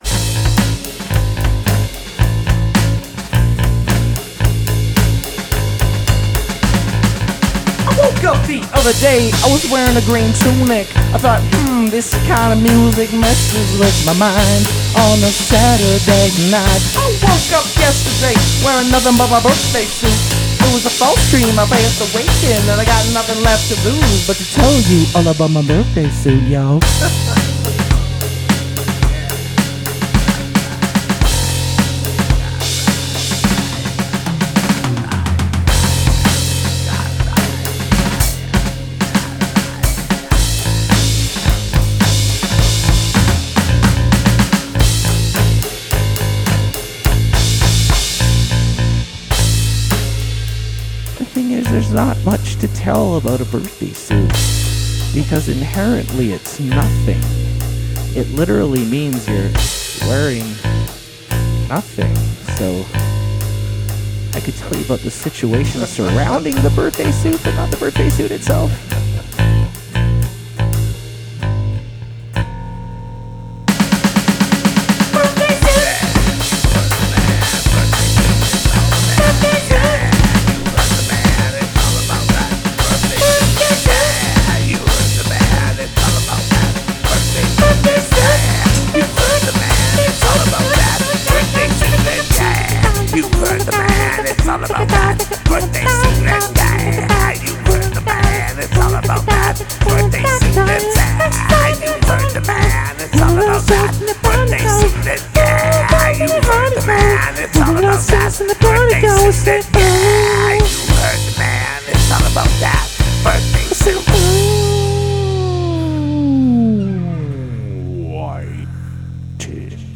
Drums
Bass Guitar
Backup Vocals
Pop 2m42s Aug 3rd, 2025 (Aug 26th, 2025)